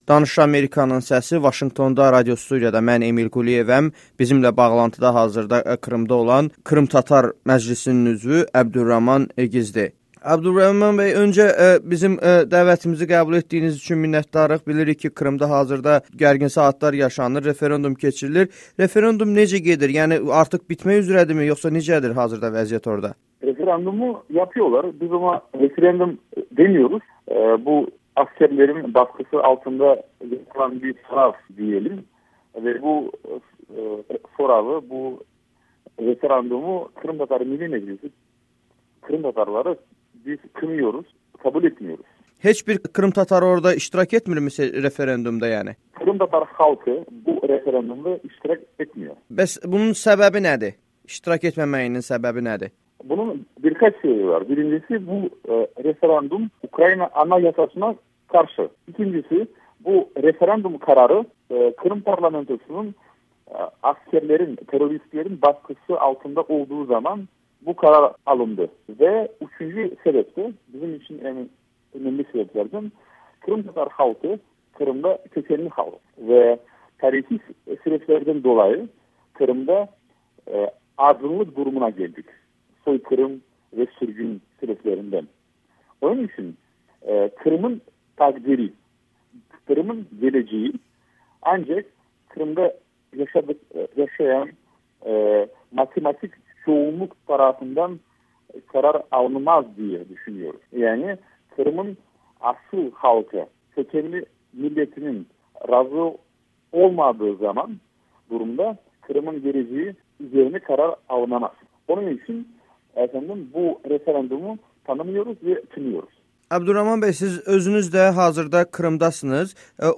Krım Tatar Məclis üzvü Əbdülrəhman Egizlə müsahibə